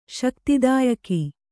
♪ śaktidāyaki